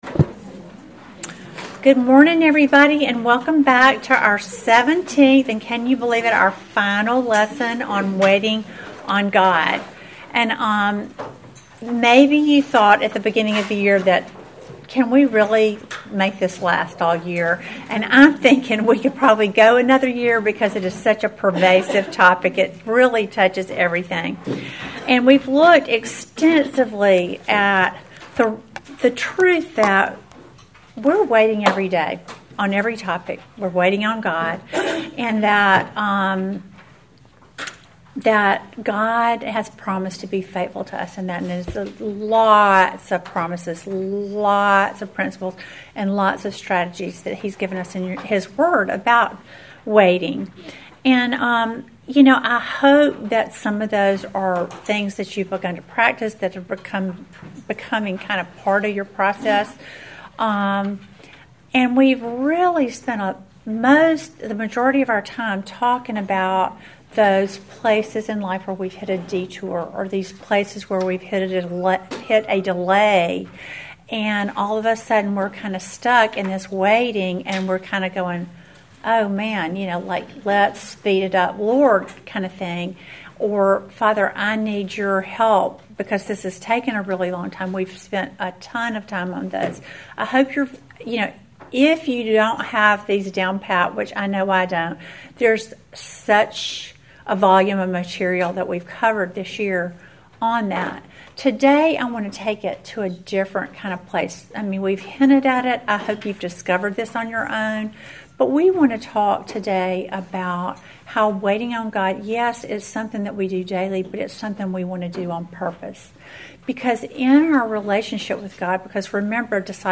Welcome to the seventeenth and final lesson in our series WAITING ON GOD!